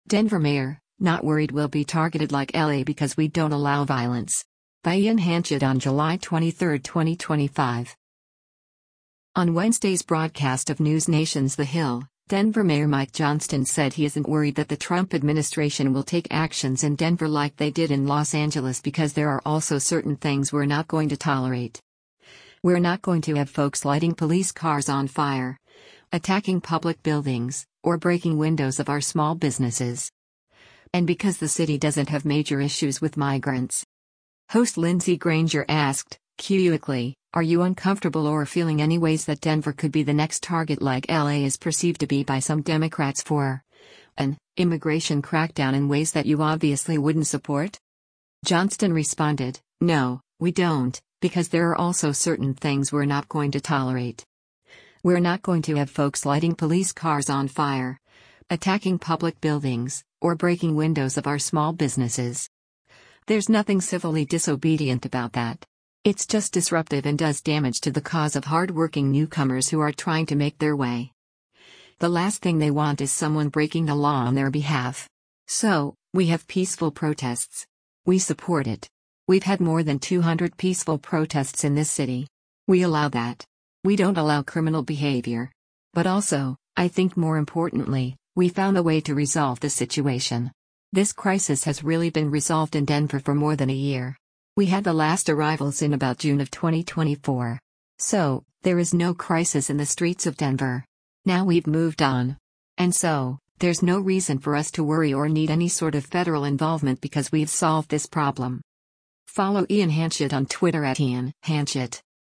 On Wednesday’s broadcast of NewsNation’s “The Hill,” Denver Mayor Mike Johnston said he isn’t worried that the Trump administration will take actions in Denver like they did in Los Angeles “because there are also certain things we’re not going to tolerate.